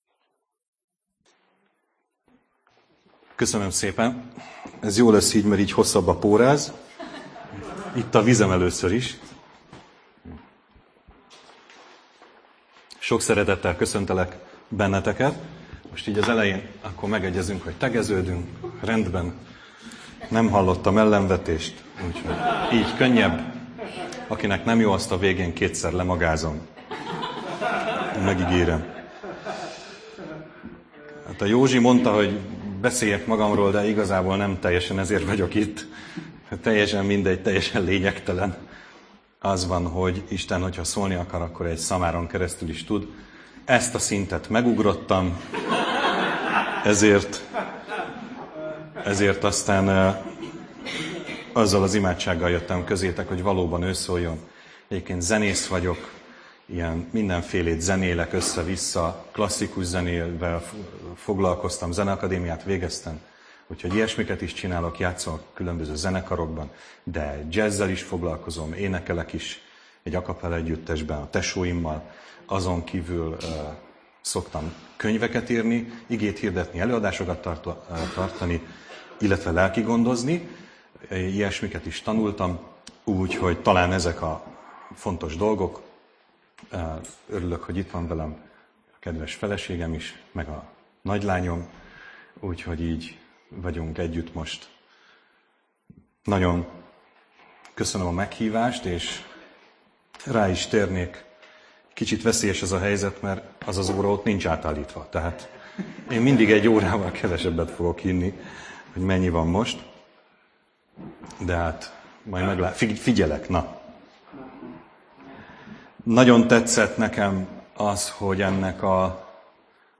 Előadások